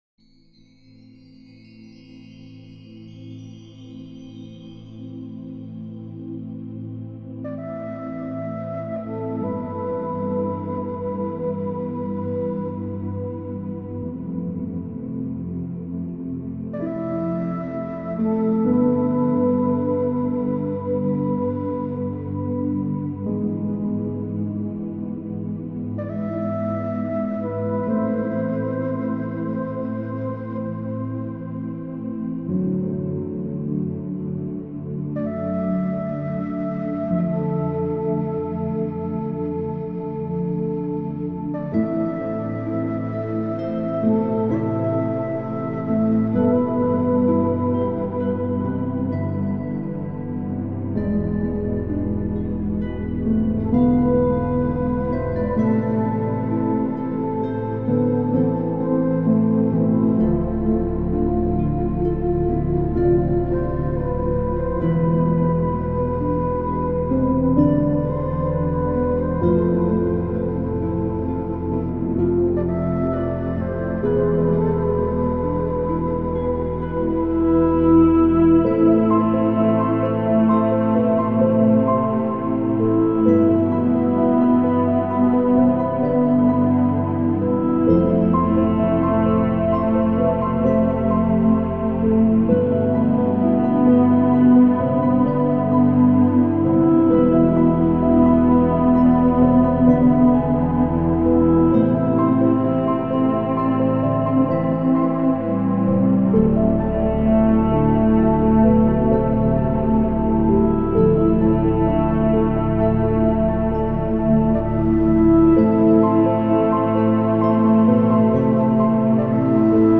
即座に眠るための豪雨と雷雨
時には水音、時には遠雷、時には耳の中の宇宙のようなノイズ。